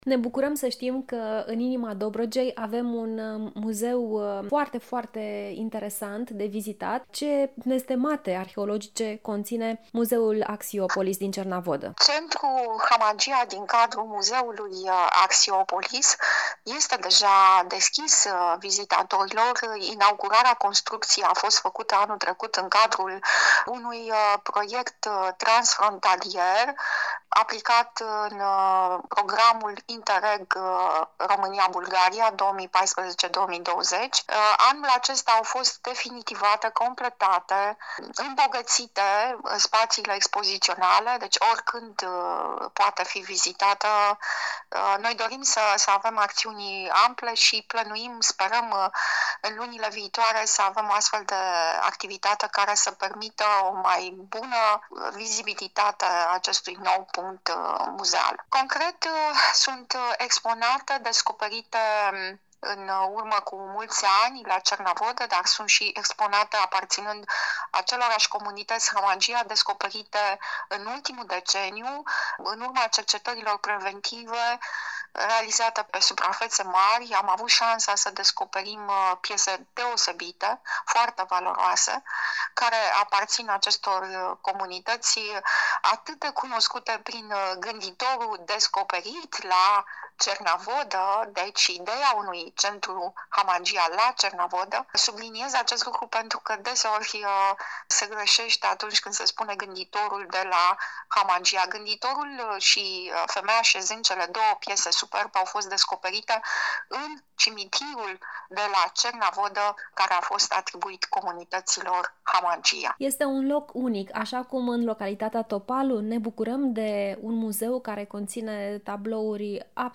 AUDIO | Axiopolis, Muzeul din Cernavodă cu piese Hamangia vechi de peste 6000 de ani, își așteaptă vizitatorii